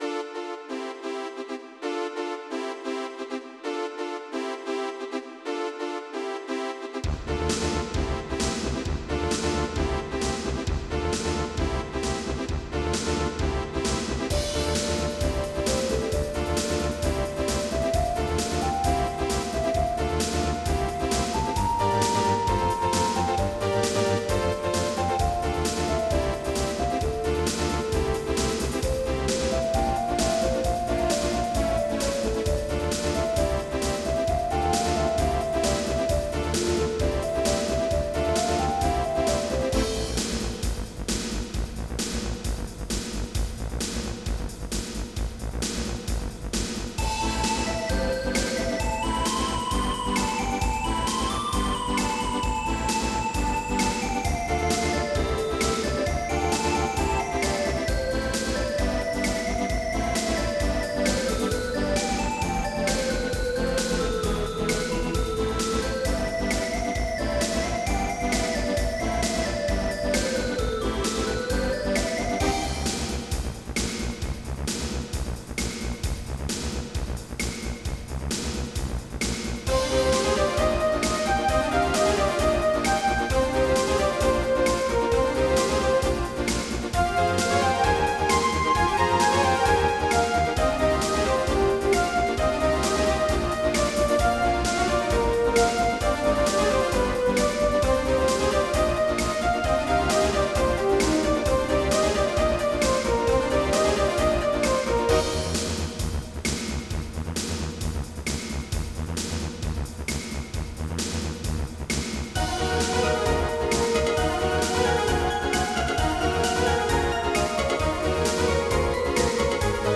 Extended MIDI